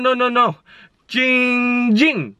Play, download and share Giiing original sound button!!!!
giiing-ging.mp3